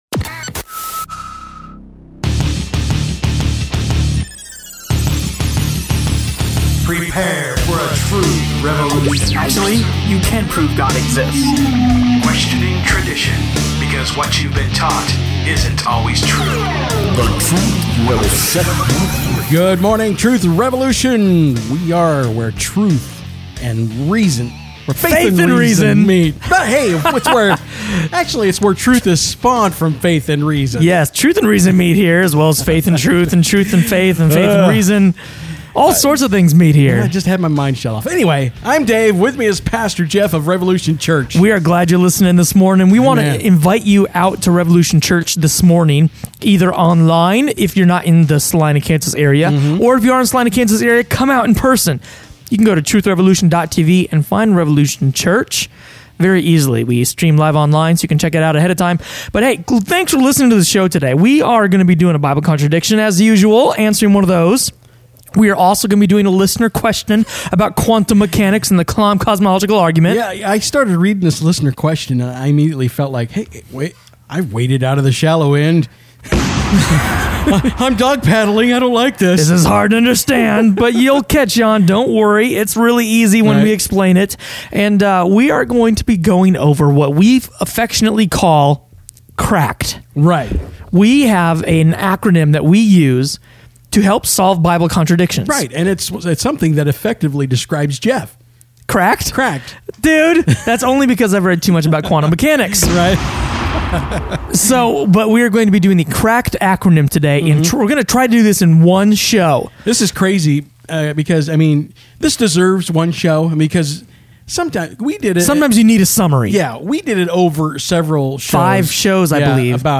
– Truth Revolution Radio Show